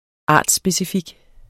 Udtale [ ˈɑˀds- ]